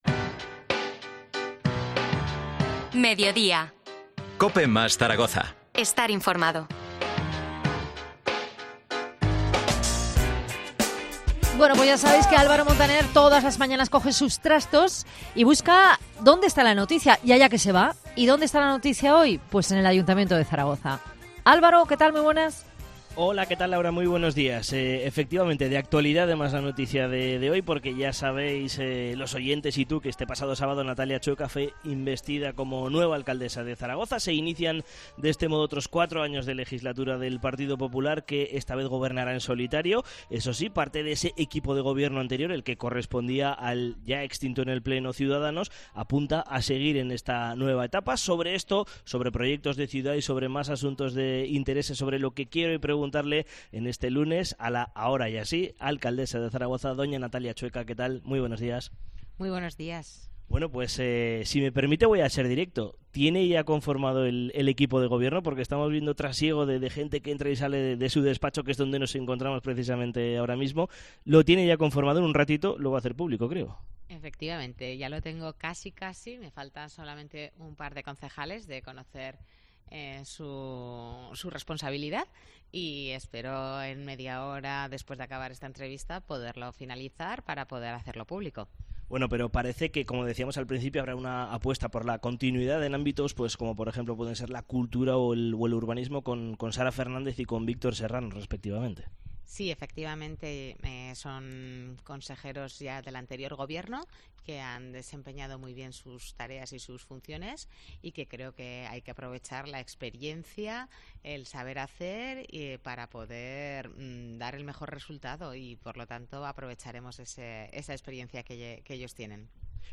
Entrevista a Natalia Chueca, alcaldesa de Zaragoza